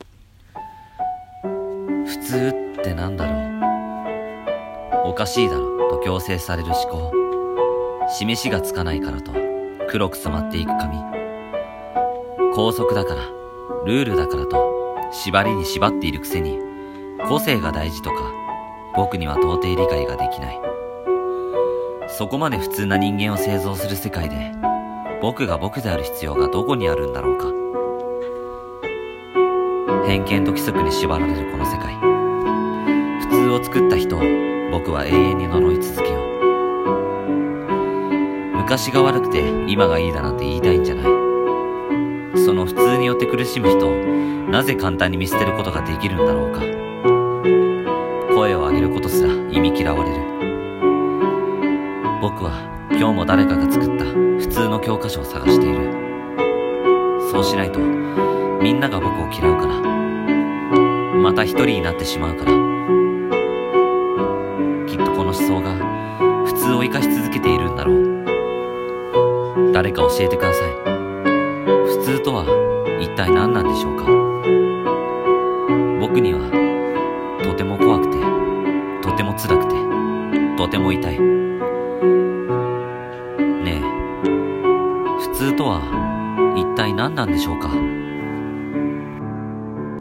一人声劇】ふつうフツウ普通